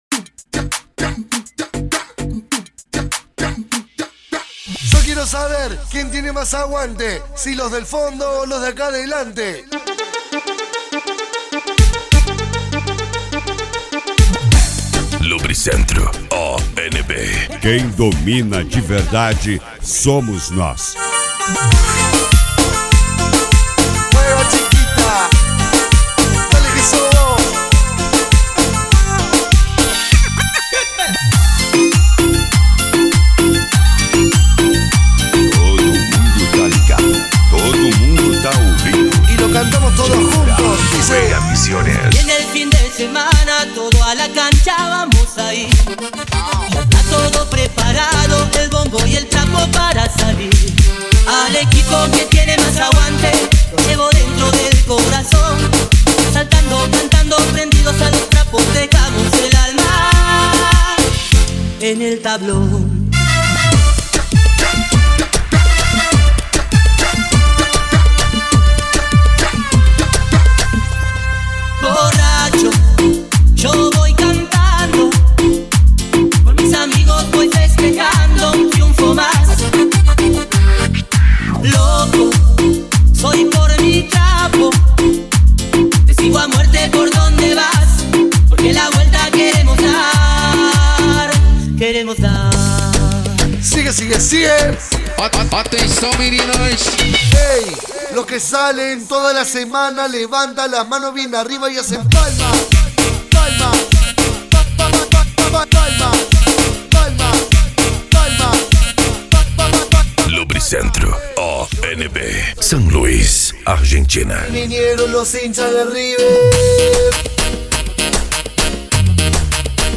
Cumbia
Funk
Remix